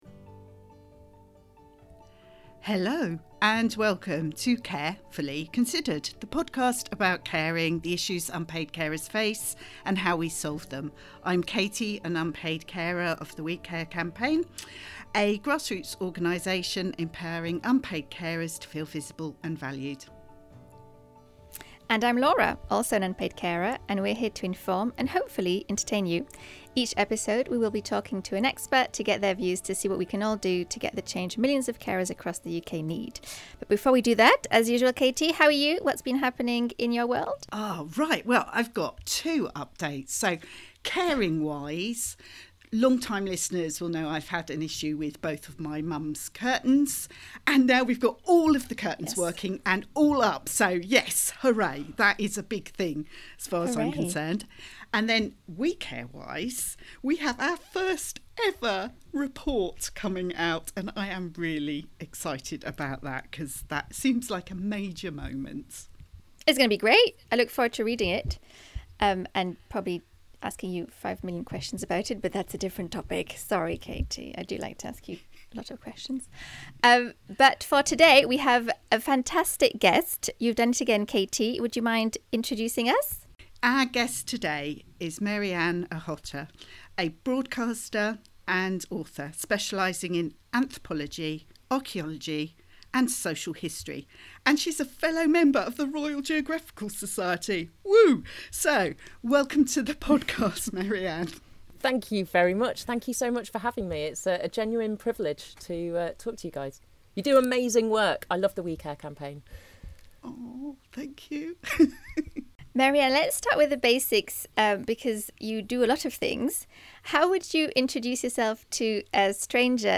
In our latest episode of Care Fully Considered we speak to Mary-Ann Ochota, a broadcaster and author specialising in anthropology, archaeology, and social history.